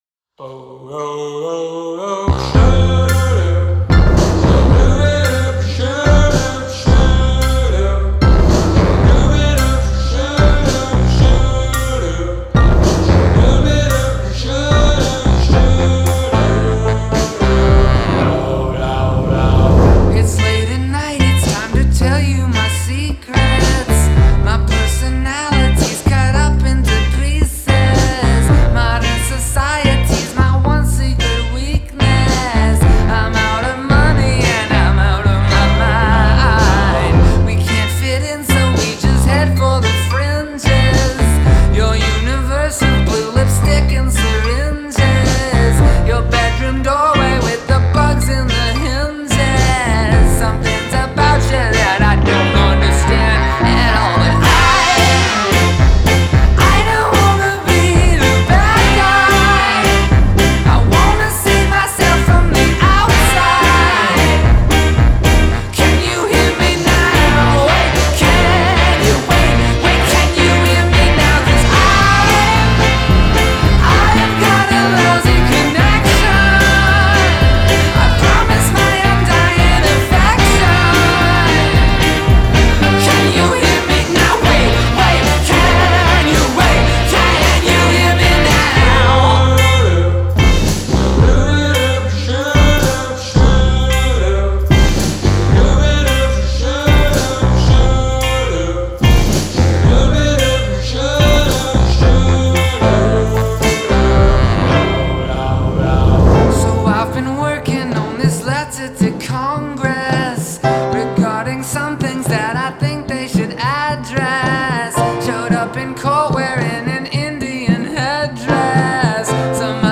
Genre: Indie Pop